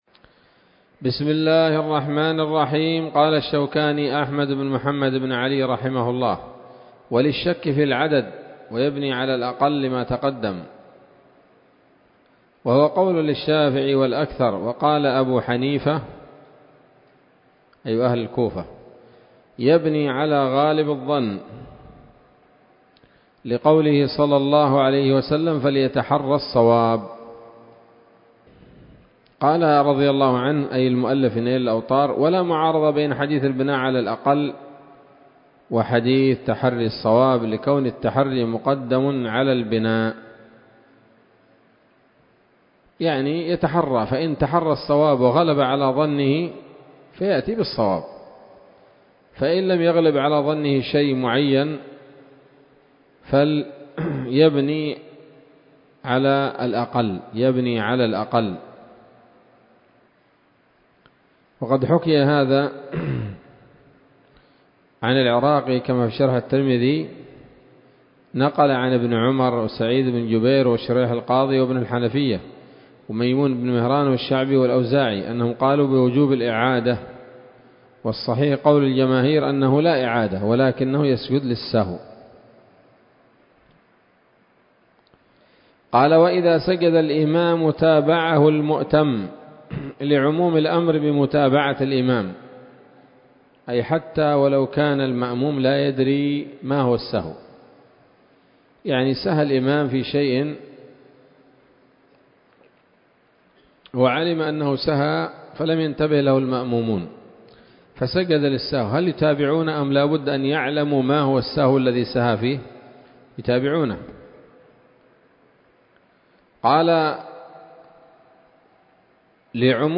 الدرس الرابع والثلاثون من كتاب الصلاة من السموط الذهبية الحاوية للدرر البهية